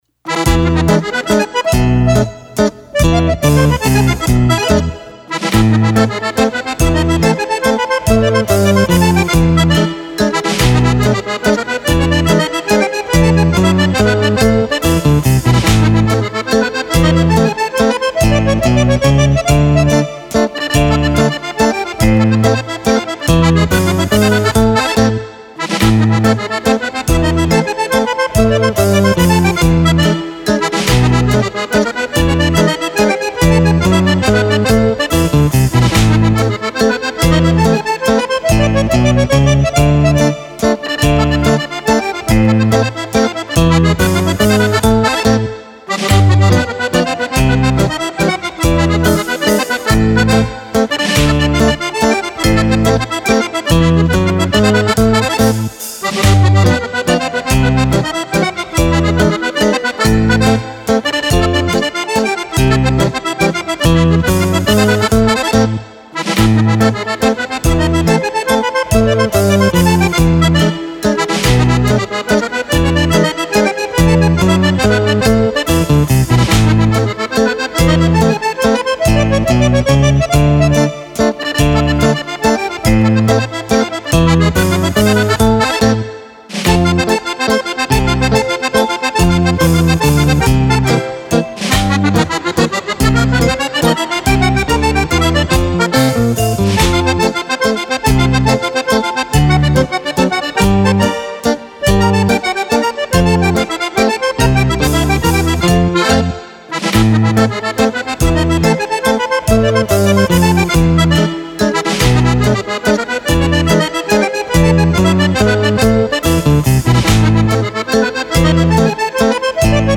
Mazurka
Album di ballabili  per Fisarmonica.
15 brani  di liscio e latino.